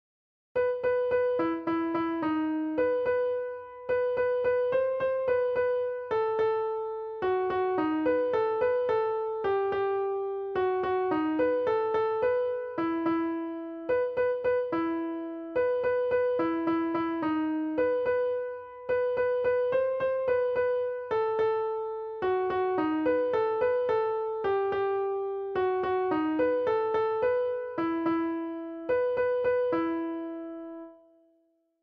І, несподівано, він таки має щось на подобі мелодії (просто «голі» ноти, без аранджування, без акомпанементу), як і годится тому, що по чужому тексту задумувалося піснею (пісняр з мене правда ще той XD ).Третій і шостий рядки у кожному стовпці поворюються двічі, шостий в останньому, як виняток, — тричі (хоча тут ситуативно).
СТИЛЬОВІ ЖАНРИ: Ліричний